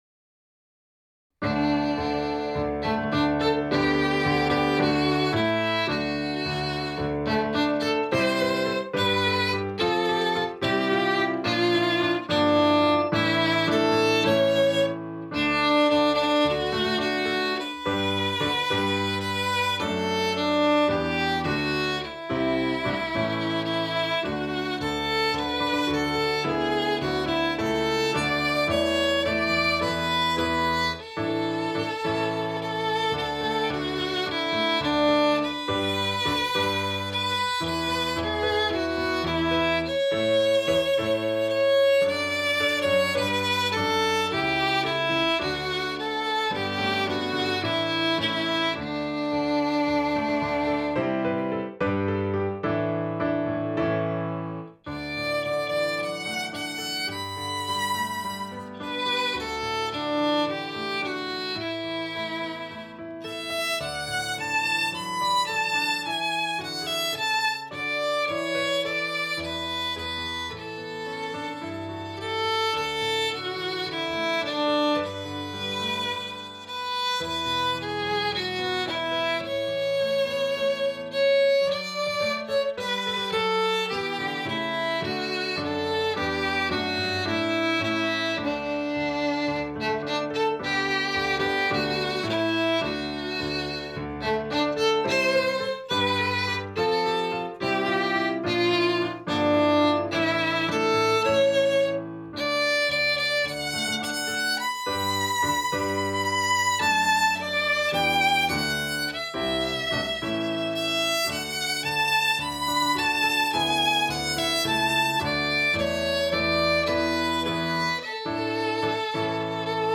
Arrangement for beginning string players
Instrumentation: Violin, Viola, Cello, Bass, Piano
lively and bold arrangement